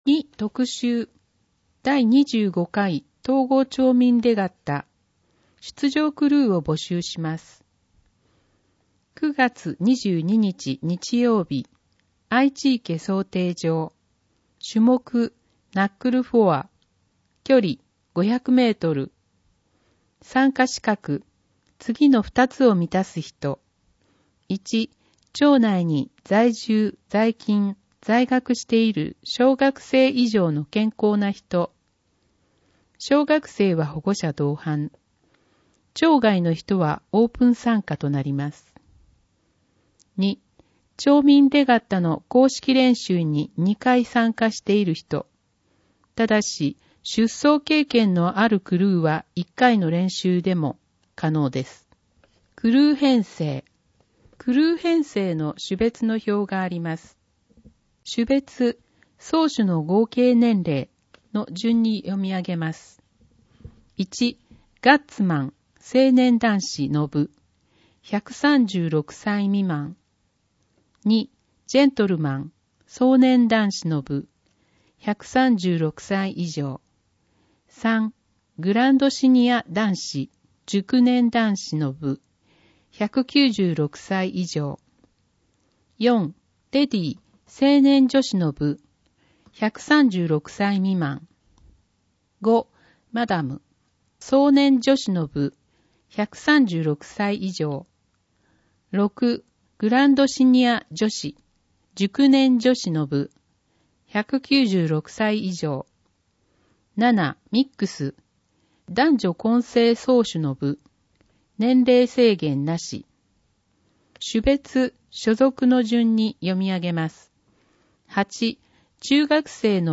広報とうごう音訳版（2019年7月号）